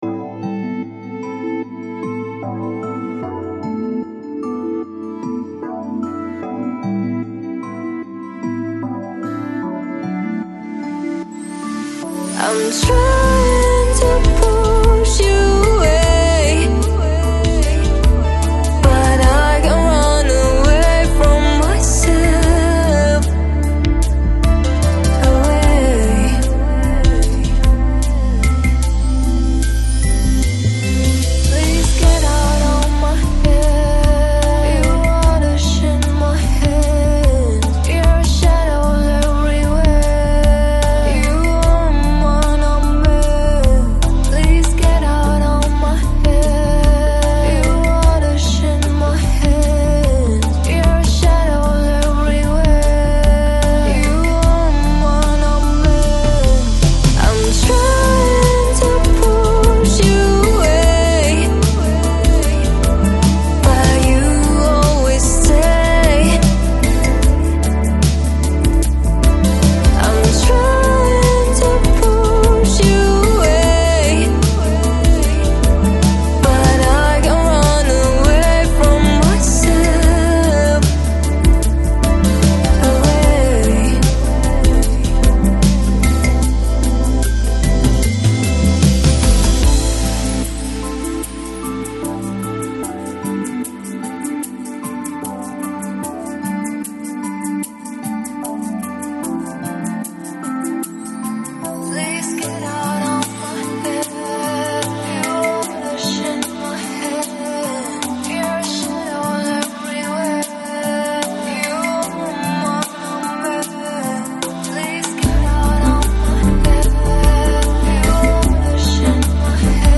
Lo-Fi, Lounge, Chillout, Vocal